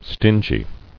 [stin·gy]